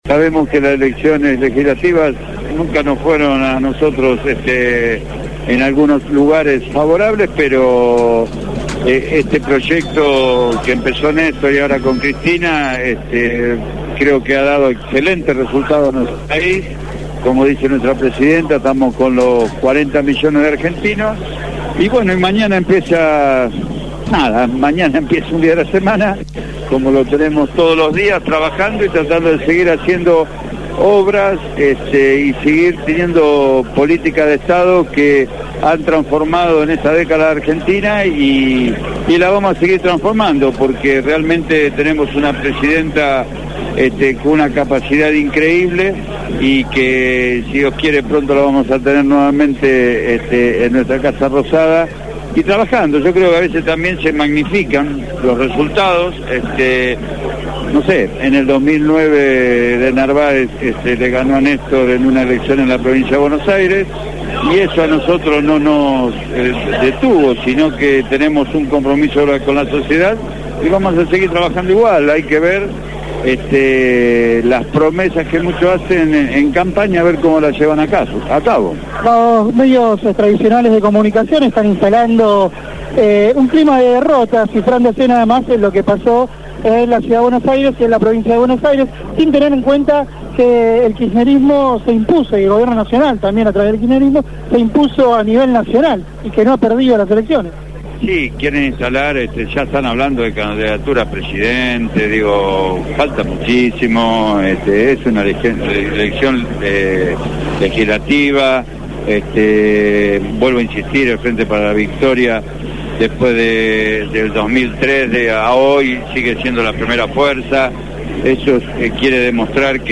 castagnetoCarlos Castagneto, viceministro de Desarrollo Social de la Nación, dejó su reflexión en la transmisión electoral de Radio Gráfica.